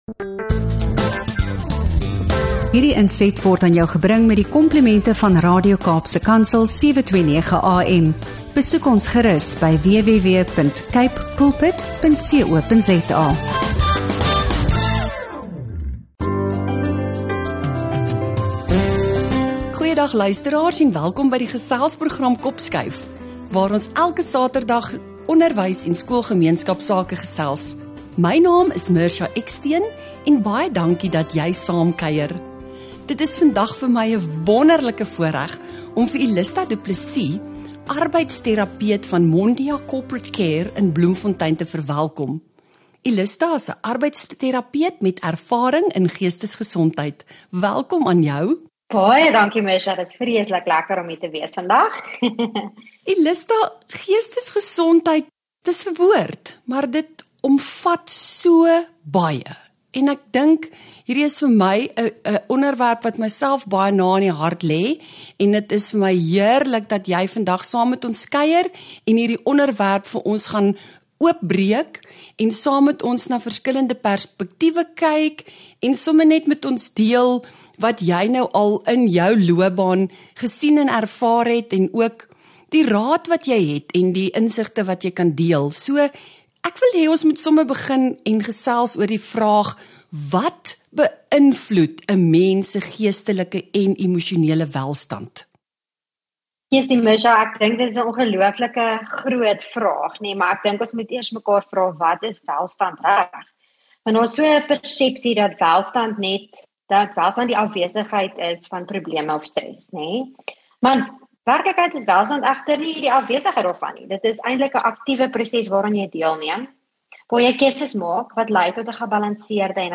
Luister na ‘n insigryke gesprek oor geestesgesondheid, emosionele veerkragtigheid, en die krag van positiewe emosies